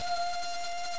Sound Effects
COLLIDE.WAV